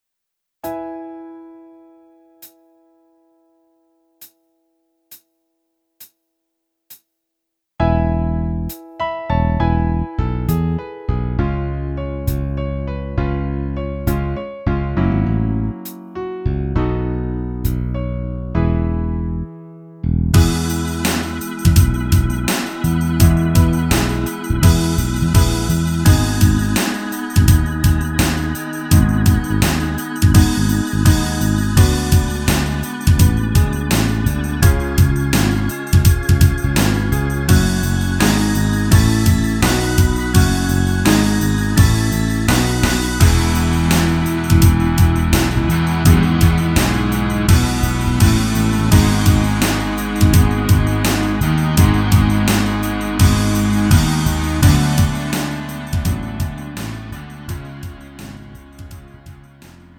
음정 -1키 4:37
장르 구분 Lite MR